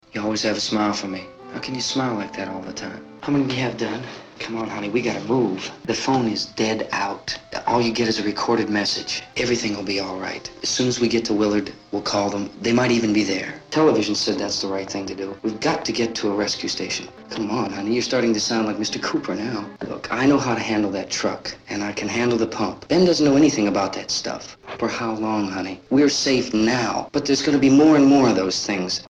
Audio snippets assembled from Night of the Living Dead (1968).
Night-of-the-Living-Dead-1968-man.mp3